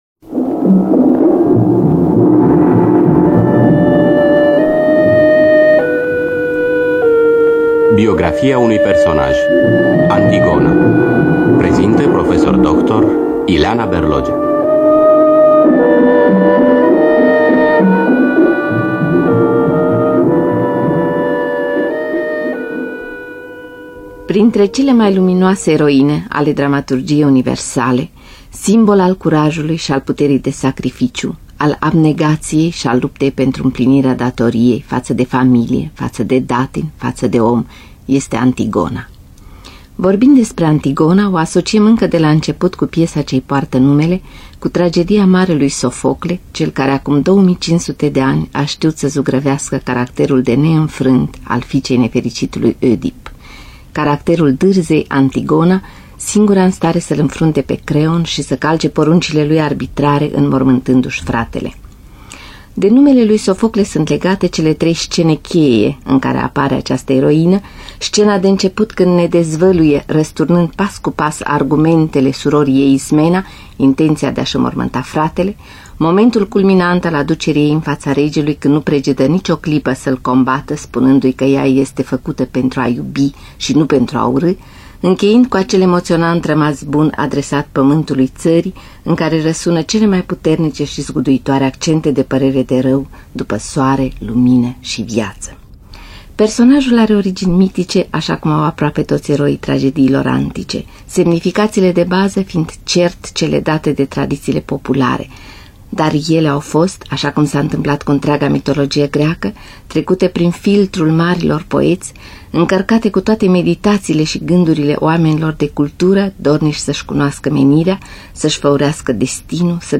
Scenariu radiofonic de Ileana Berlogea.